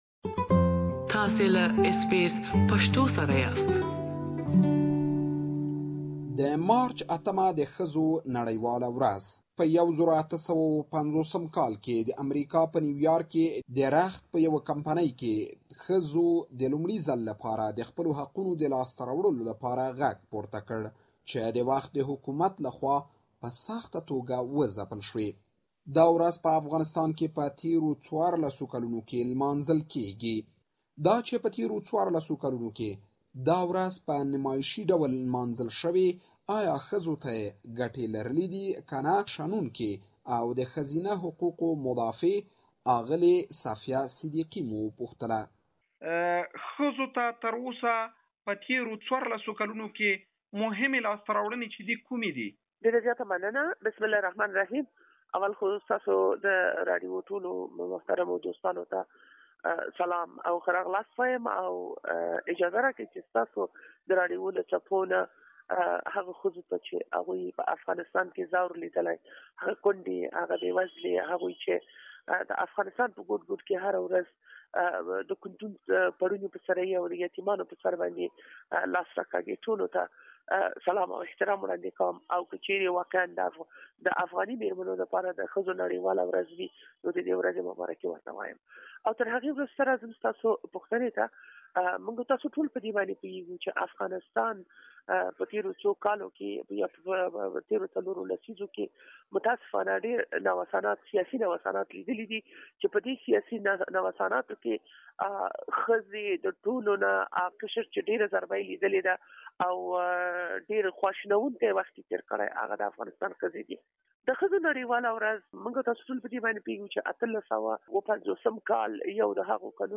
Women's right activist Mrs Safia Siddiqi believes that nothing of significance have been achieved for women's right and still faces challenges. Please listen to the full interview here.